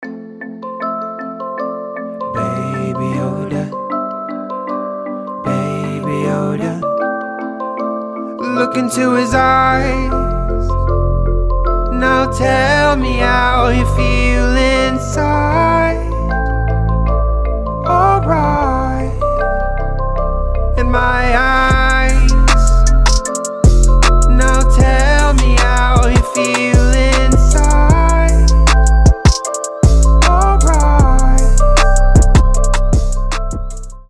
• Качество: 320, Stereo
мужской вокал
милые
мелодичные
Trap
Музыкальная шкатулка